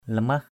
/la-mah/ (cv.) limah l{MH [Cam M]
lamah.mp3